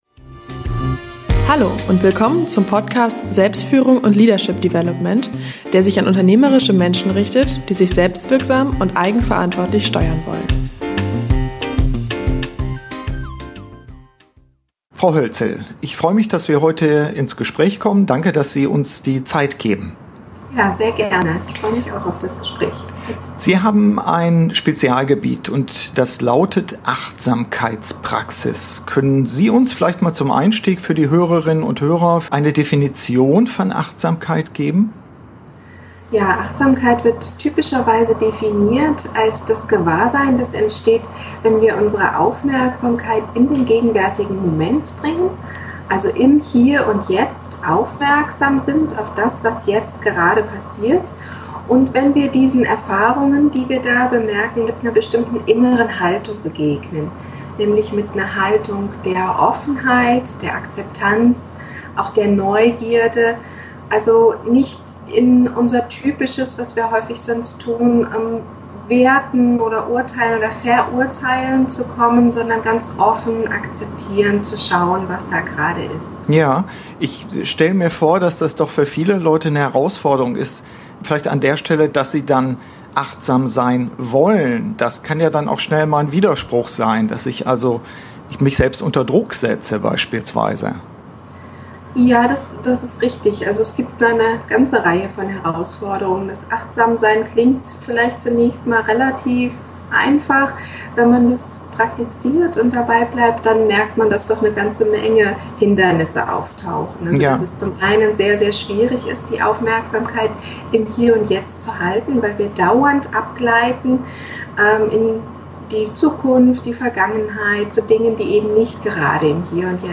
Im Interview erörtern wir, wie gestresste Manager im Alltag einfache Methoden des Innehaltens für sich lernen können.